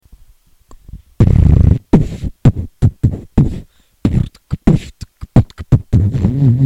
Выкладываем видео / аудио с битбоксом
2)Brr Pf B B B Pf
Brr tk Pf tk B tk BB w w w ( низкая тональность)
обидно в самом то деле) просто я на ноутовский микрофон записую)